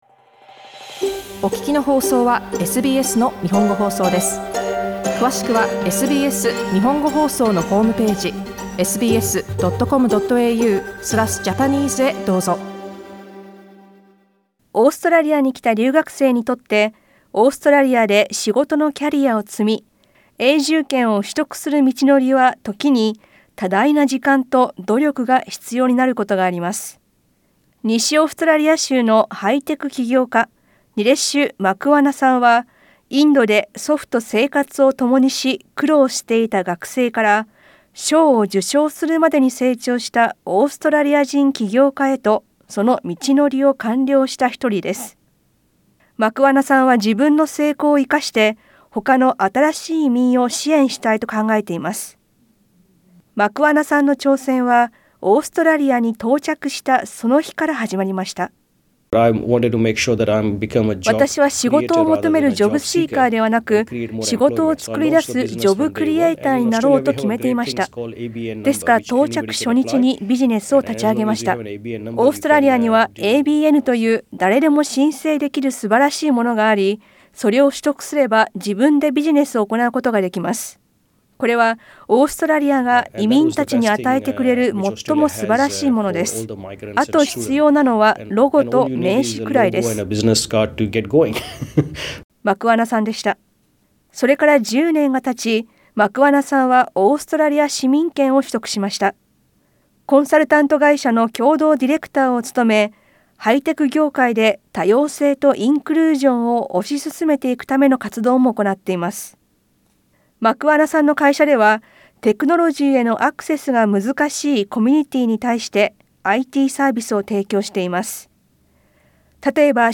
詳しい音声リポートは写真をクリックしてどうぞ。